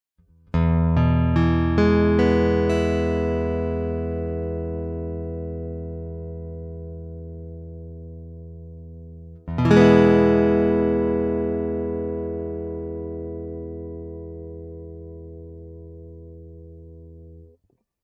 Вот некоторые звуковые файлы (ничего особенного, просто арпеджио ми мажор сопровождаемый мажорным аккордом) с моим Macassar Ebony T5 в различных положениях (от 1 до 5):
Это прямо из гитары в мой 4-х дорожечный рекордер Fostex CompactFlash.
Я думаю, что позиции 1 и 2 на Т5 дают очень акустические звуки, и я сомневаюсь, что кто-то может сказать, что они не были акустическими (по крайней мере, по сравнению с Taylor акустическими) играется через усилитель.